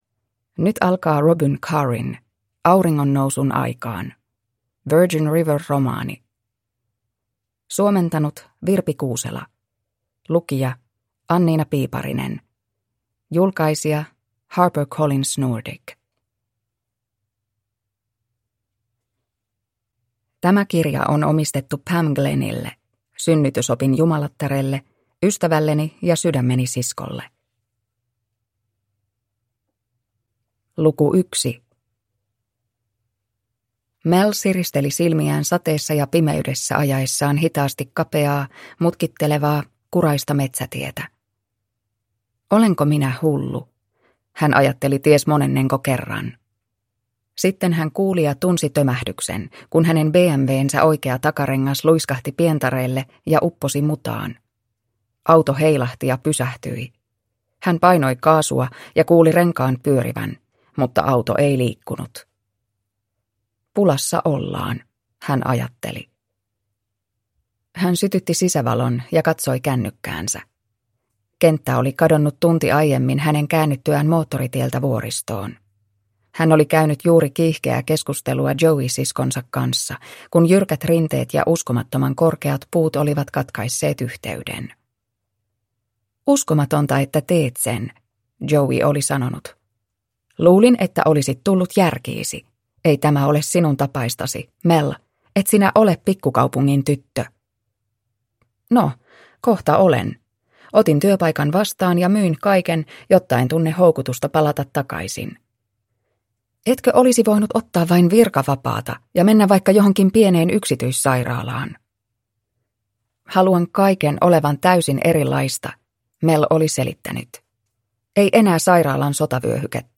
Auringonnousun aikaan – Ljudbok – Laddas ner